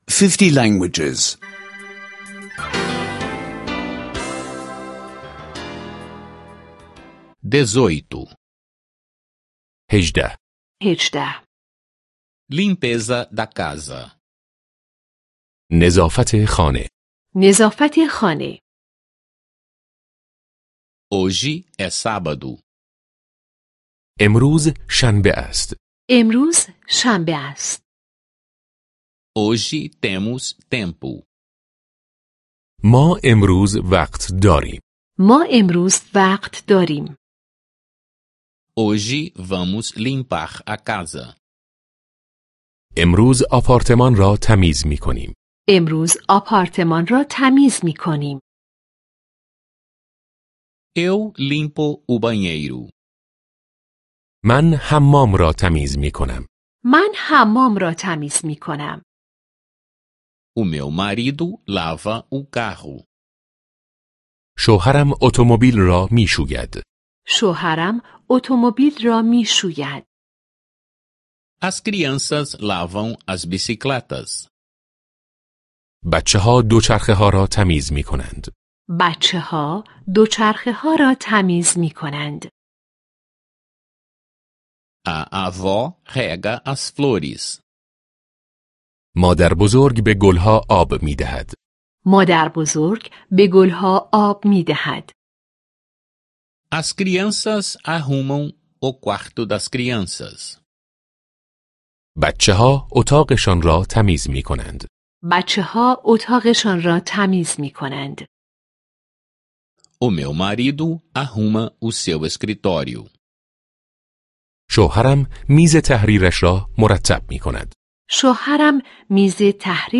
Aulas de persa em áudio — download grátis